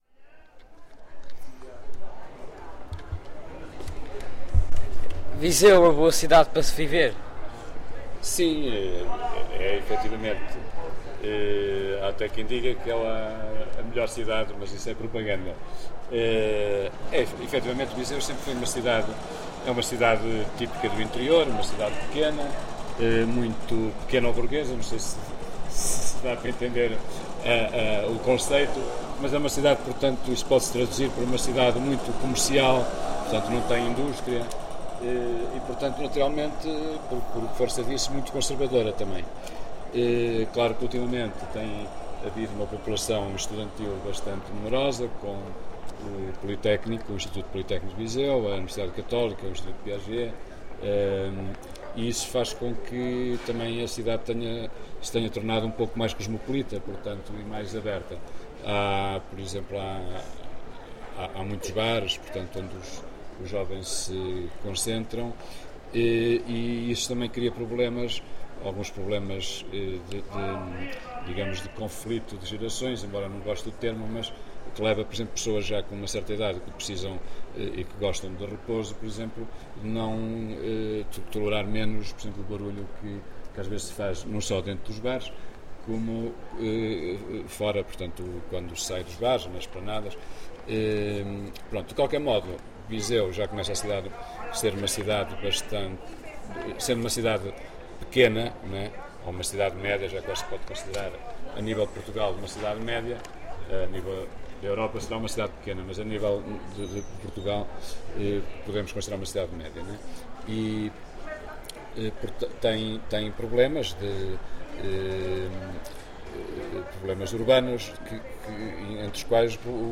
Entrevista sobre a qualidade de vida em Viseu, as rotundas, o ruído e outros temas ligados ao espaço público da cidade. Gravado com Fostex FR-2LE e um microfone Tellinga.
Tipo de Prática: Inquérito Etnográfico, Inquérito Oral